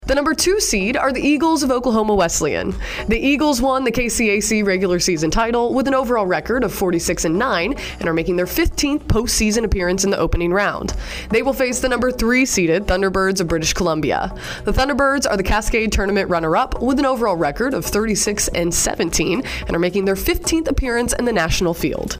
Here's how it sounded on the selection show.
OKWU Baseball Selection Show.mp3